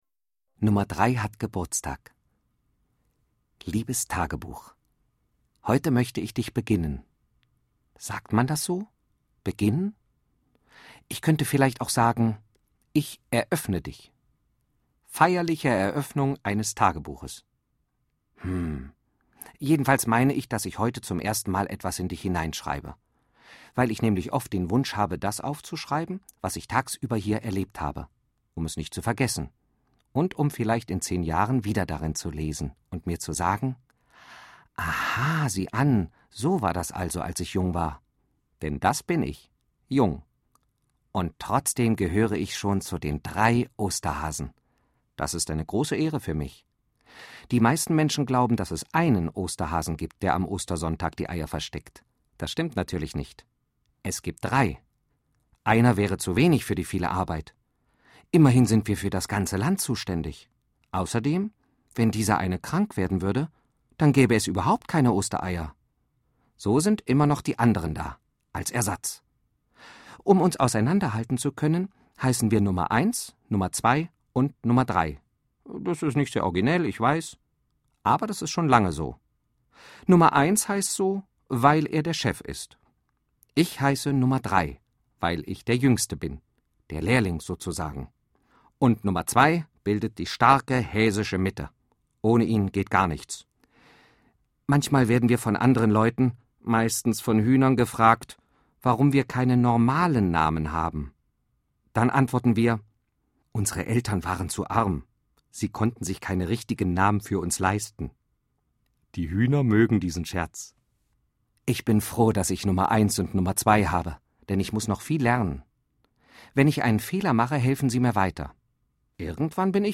Reihe/Serie DAV Lesung für Kinder